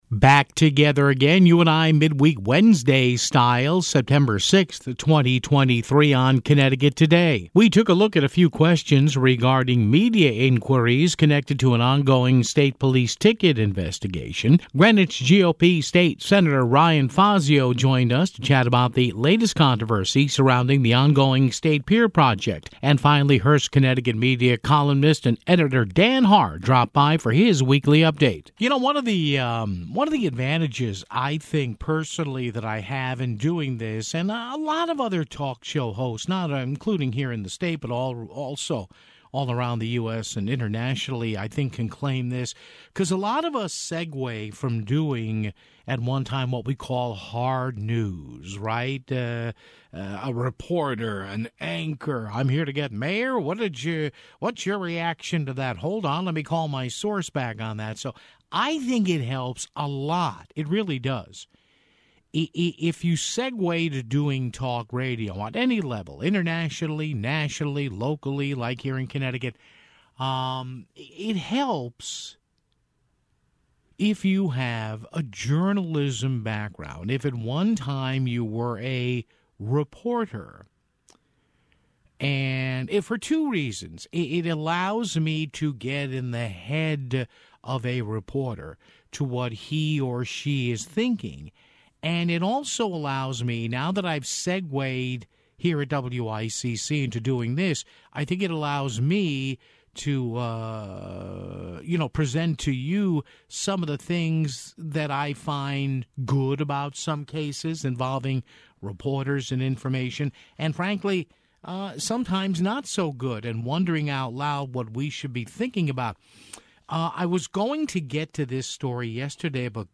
Greenwich GOP State Sen. Ryan Fazio joined us to chat about the latest controversy surrounding the ongoing State Pier project (12:00).